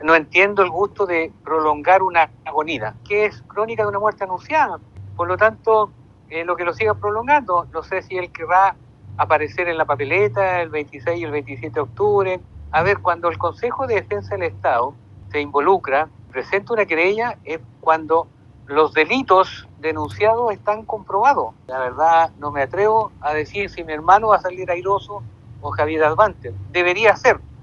Mientras que el diputado de derecha, Leonidas Romero